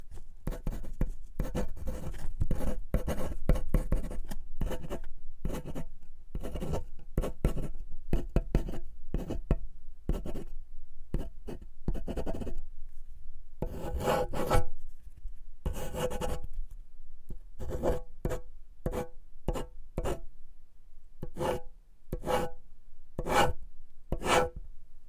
写作 " 玻璃上的一叠纸上的细尖笔
描述：记录在带有SM81和便宜的akg SDC的SD 702上，不记得哪一个只是想要变化。不打算作为立体声录音只有2个麦克风选项。没有EQ不低端滚动所以它有一个丰富的低端，你可以驯服品尝。